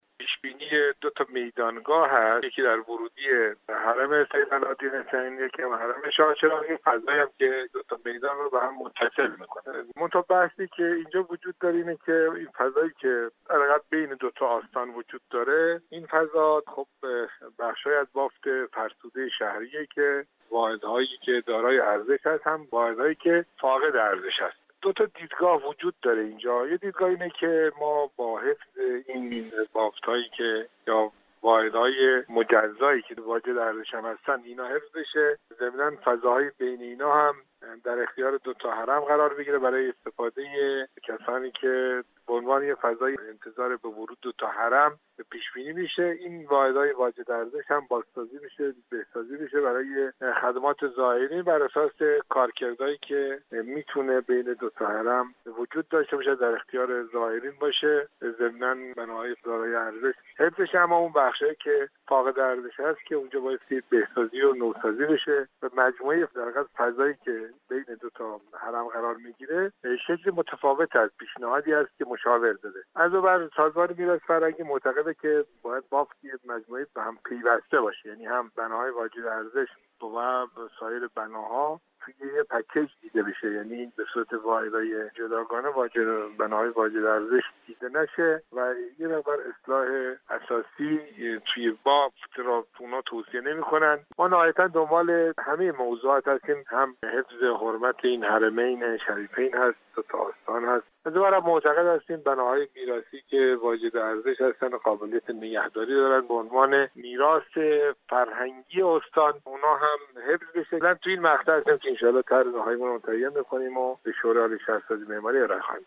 به گزارش خبر رادیو زیارت ، رضایی معاون امور عمرانی استانداری فارس درباره طرح پیشنهادی به خبر رادیو زیارت گفت: